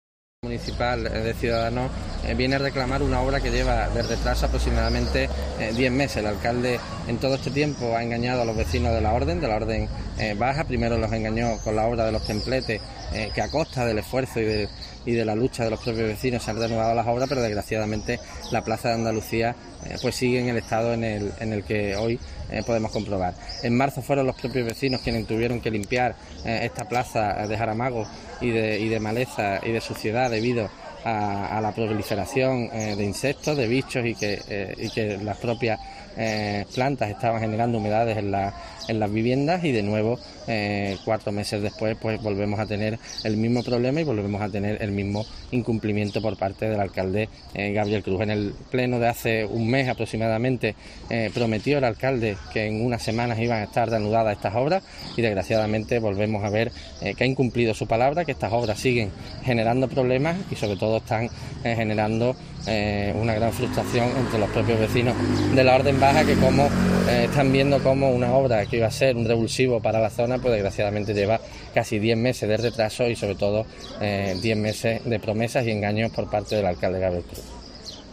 Guillermo García de Longoria, portavoz de Cs en el Ayto Huelva